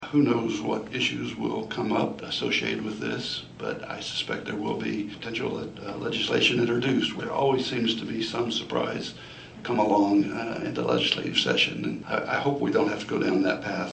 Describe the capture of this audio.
MANHATTAN — The Manhattan-Ogden USD 383 Board of Education held a special work session Wednesday with Kansas lawmakers regarding some of the issues they hope to see brought up in the upcoming legislative session.